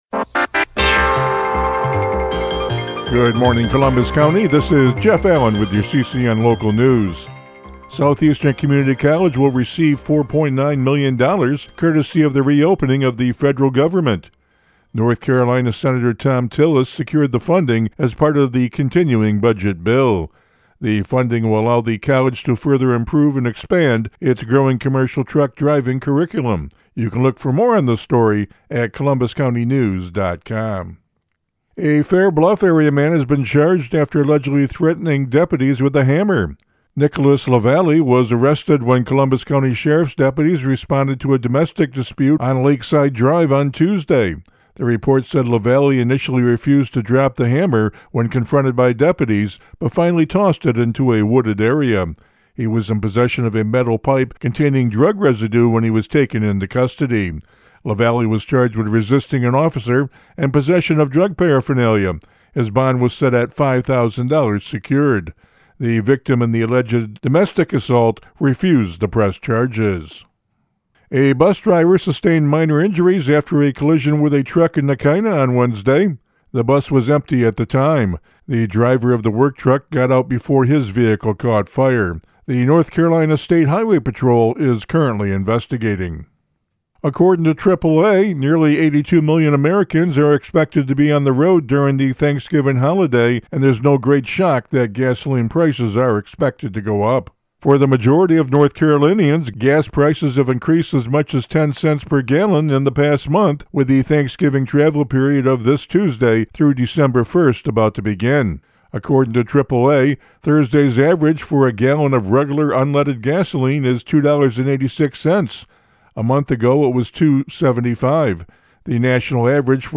CCN Radio News — Morning Report for November 21, 2025